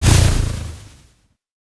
SFX monster_hit_ghost.wav